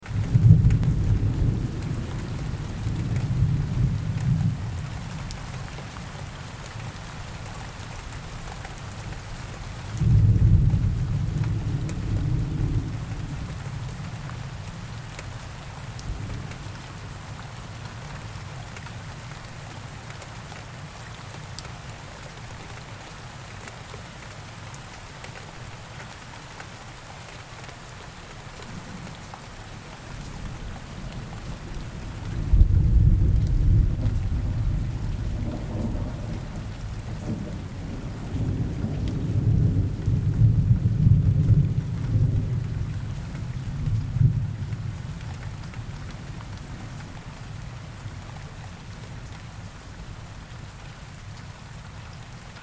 جلوه های صوتی
دانلود آهنگ طوفان 21 از افکت صوتی طبیعت و محیط
دانلود صدای طوفان 21 از ساعد نیوز با لینک مستقیم و کیفیت بالا